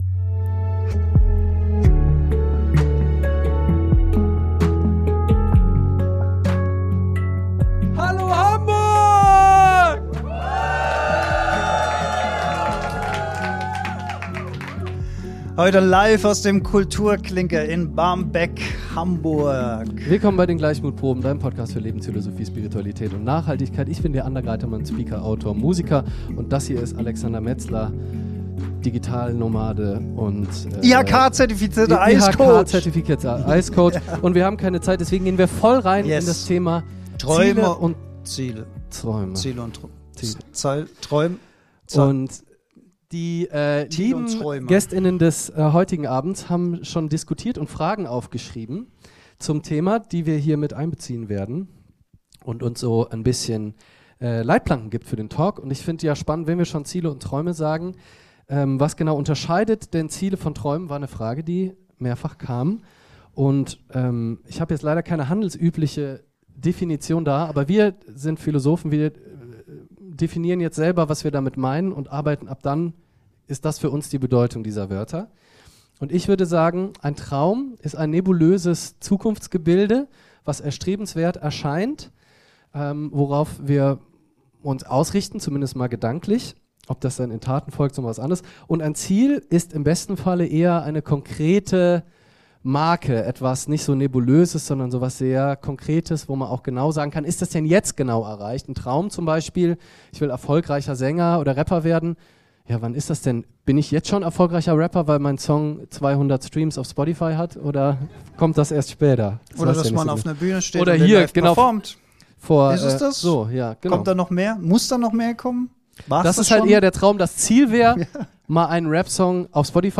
Ziele und Träume – live aus Hamburg ~ GLEICHMUTPROBEN | Lebensphilosophie, Spiritualität und Nachhaltigkeit Podcast
Live aus dem Kulturkliinker in Hamburg Barmbek